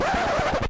record scratch